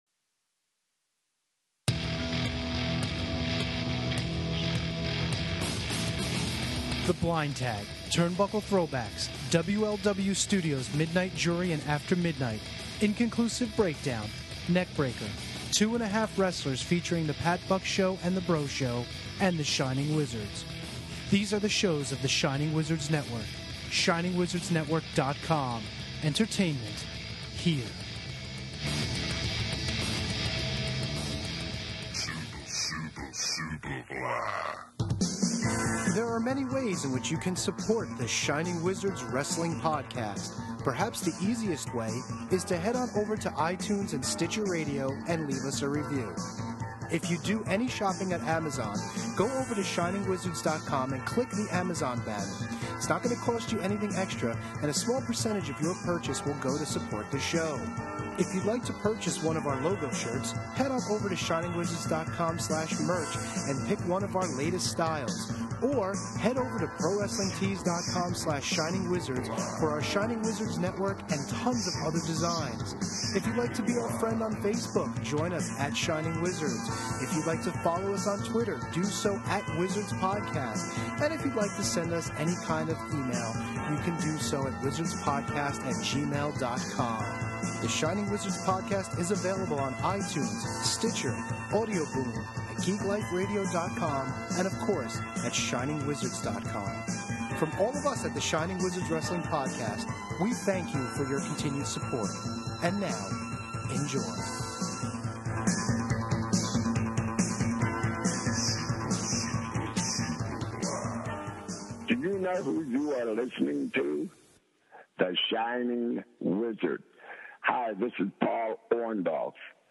Plus your calls!